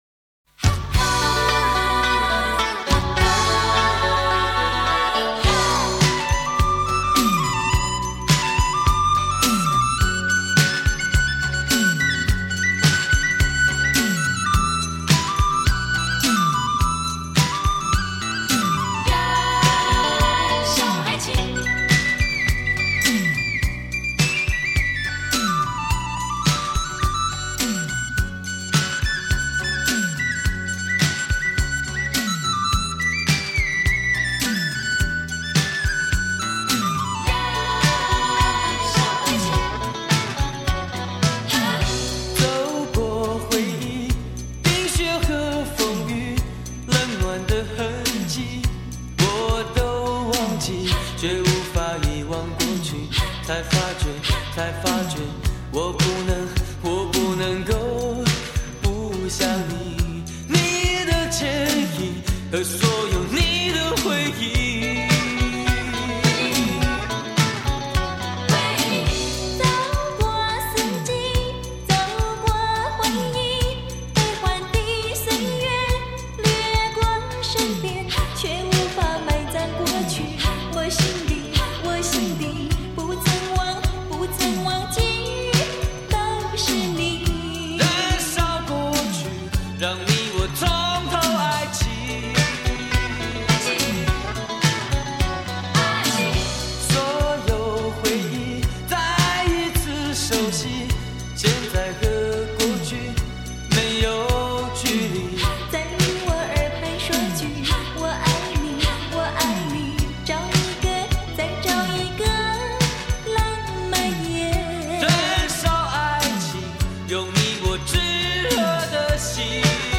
曲风是传统泰国式音乐+一点点的流行乐，听起来很有一番风味...